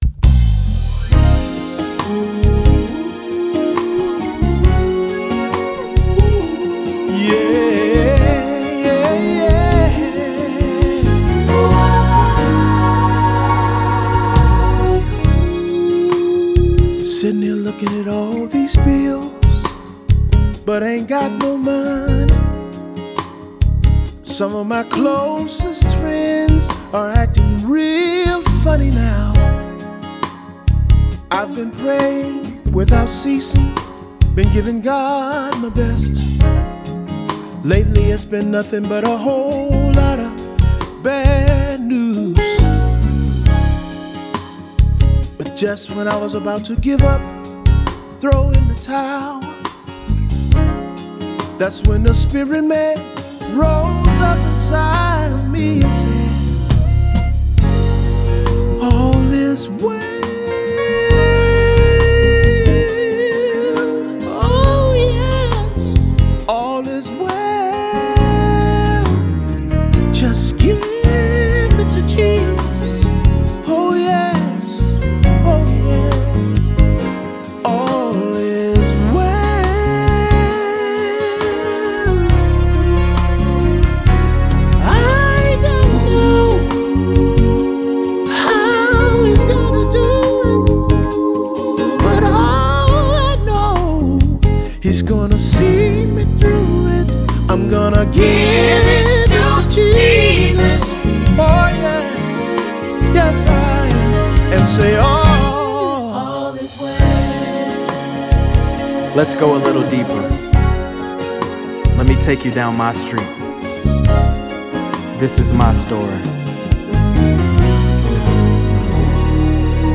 In the world of gospel music
a dynamic spin on his single
is a euphonic and passionate collaboration